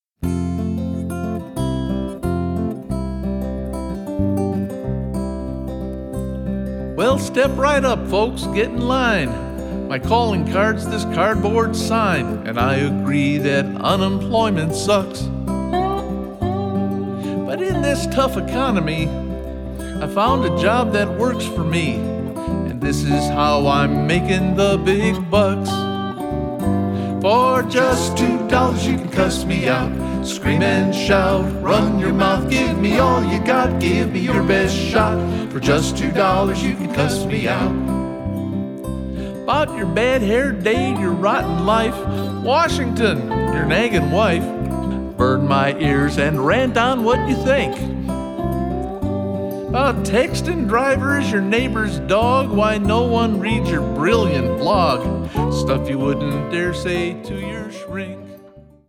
--funny songs and parodies